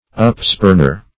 Search Result for " upspurner" : The Collaborative International Dictionary of English v.0.48: Upspurner \Up"spurn`er\, n. A spurner or contemner; a despiser; a scoffer.